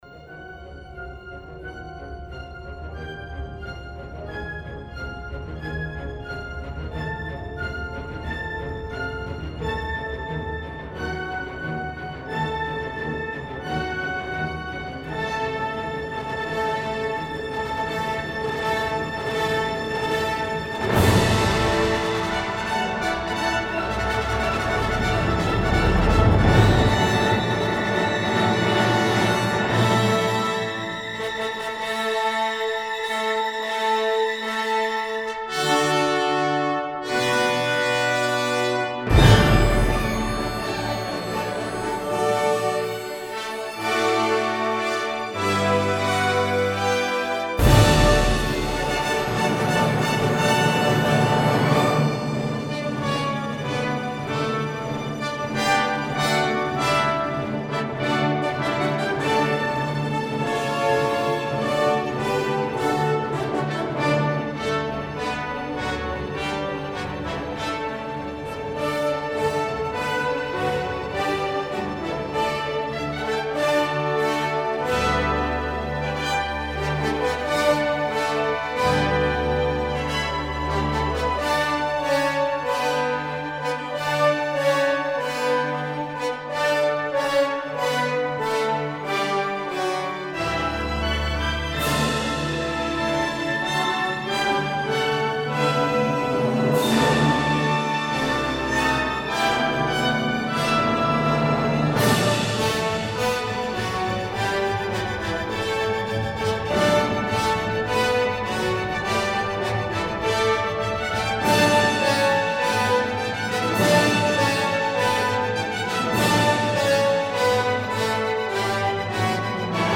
Zinman and the Tonhalle play the end of the first symphony: Stürmisch bewegt.